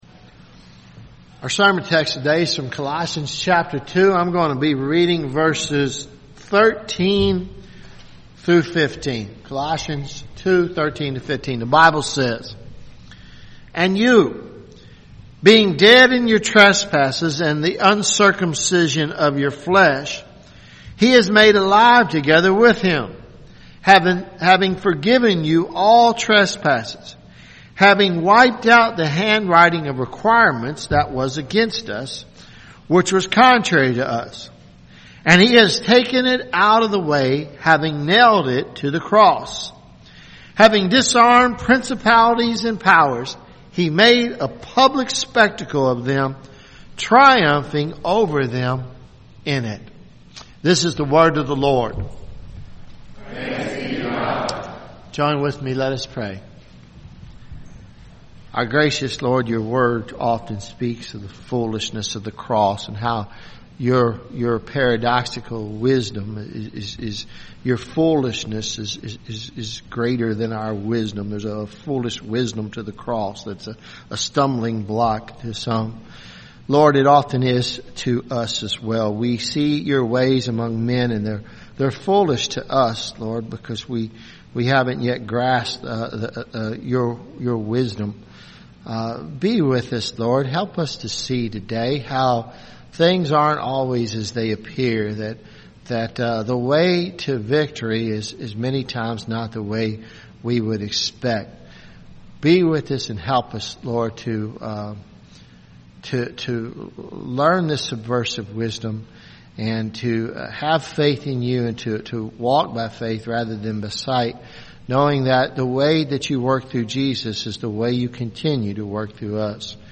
Sermons Mar 25 2018 “Christus Victor